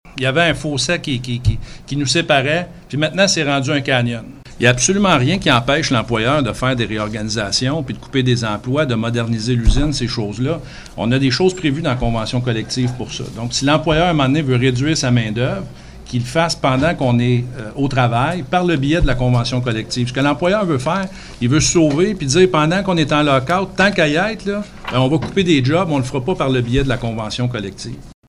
En conférence de presse mercredi, les représentants du Syndicat des Métallos ont dénoncé la mauvaise foi de l’employeur qui a formulé, en pleine négociation, de nouvelles demandes.